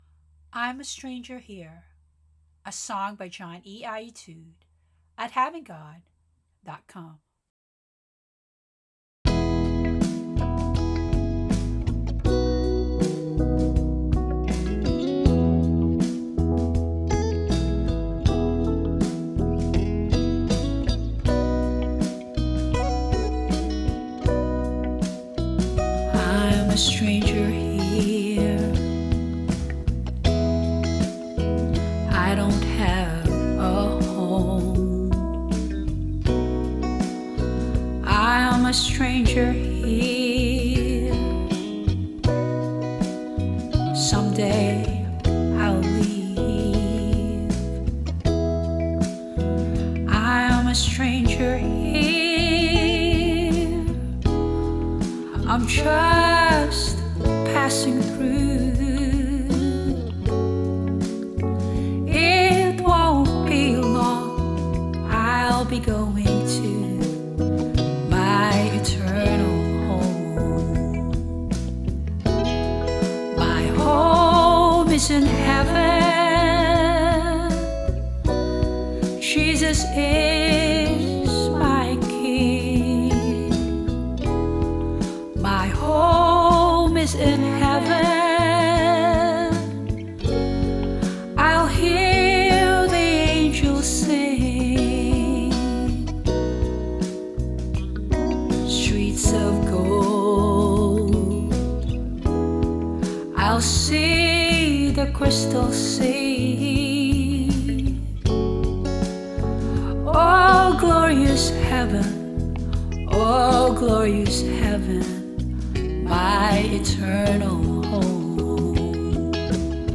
Vocals
Instruments performed by Band In A Box.